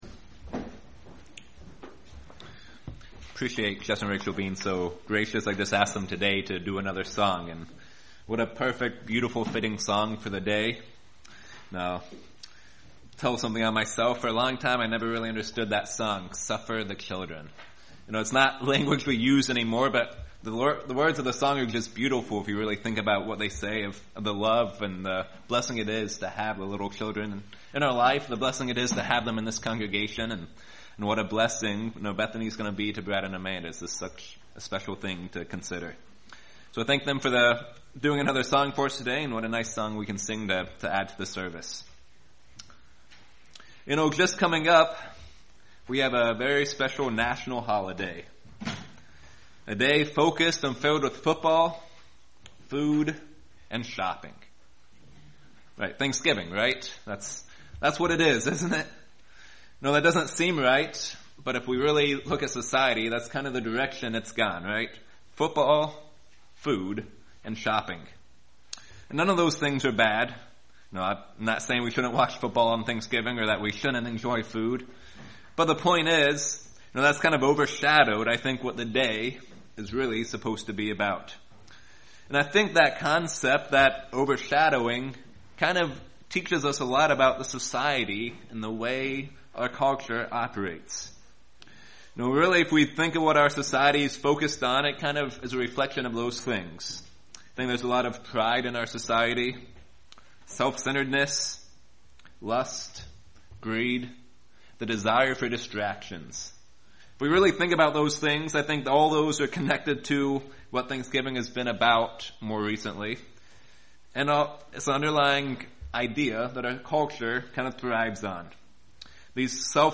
Sermons
Given in Lehigh Valley, PA Lewistown, PA York, PA